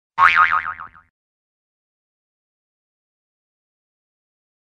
Звук пружины для монтажа
• Категория: Пружина
• Качество: Высокое